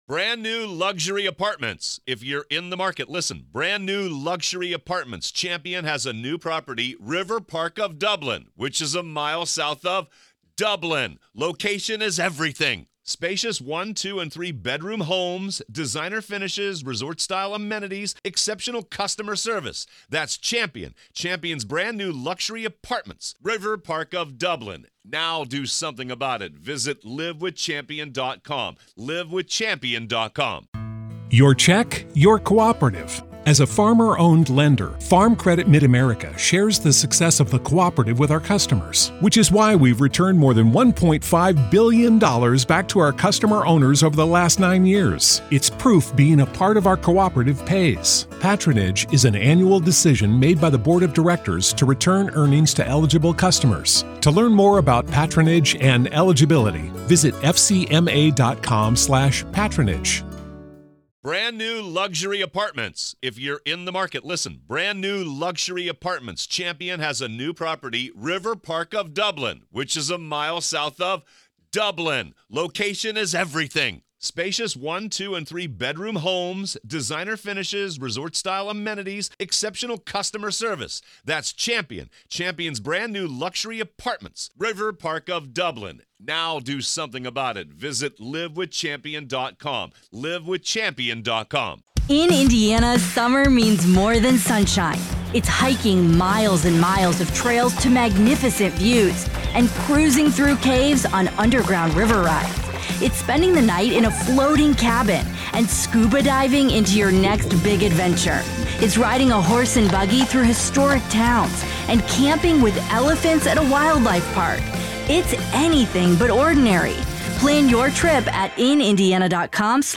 Trial